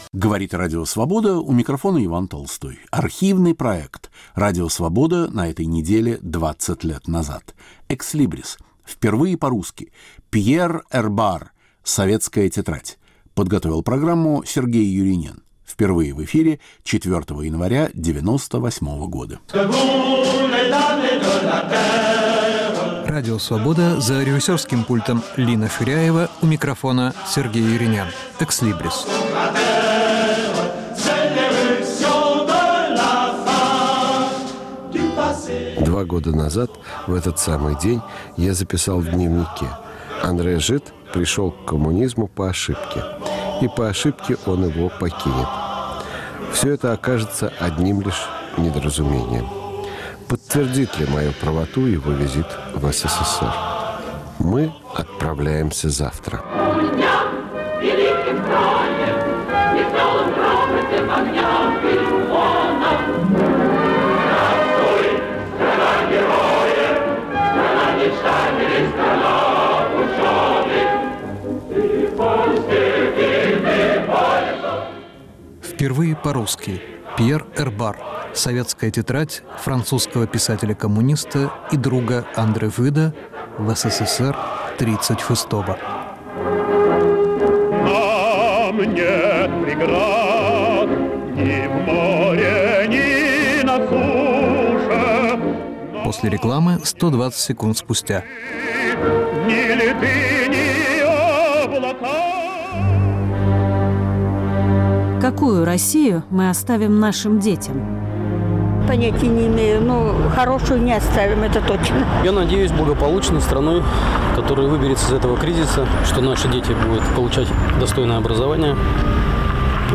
Архивный проект. Иван Толстой выбирает из нашего эфира по-прежнему актуальное и оказавшееся вечным.